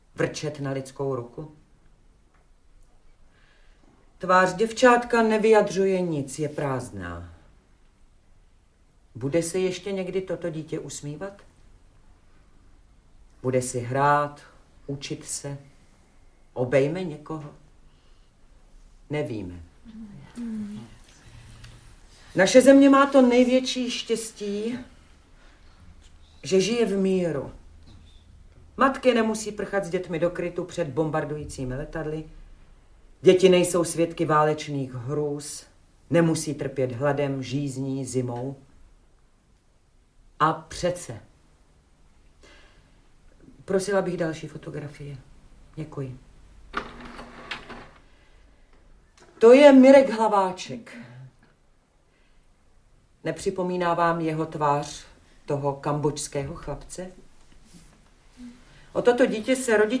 Audiobook
Audiobooks » Short Stories
Read: Vilma Cibulková